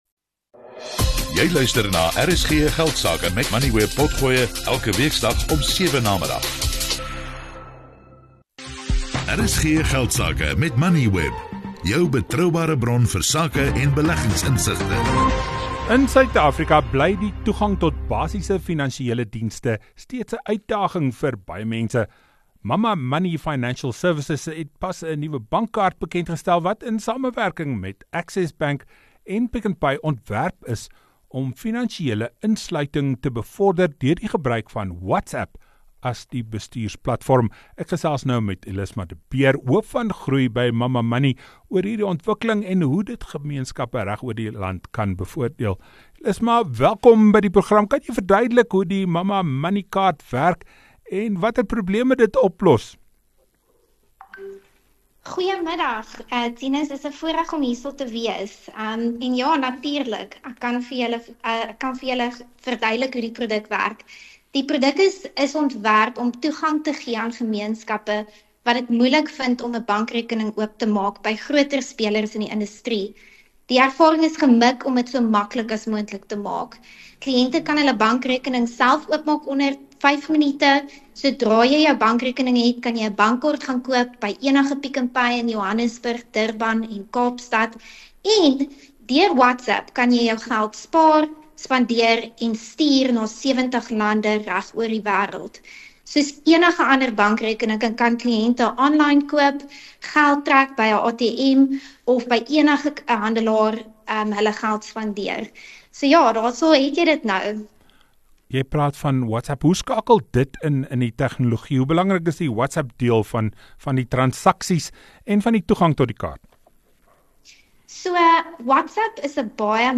RSG Geldsake is die grootste sakeprogram in Suid Afrika. Dit fokus op belangrike sakenuus, menings en beleggingsinsigte. RSG Geldsake word elke weeksdag tussen 18:10 en 19:00 op RSG (101 – 104 FM) uitgesaai.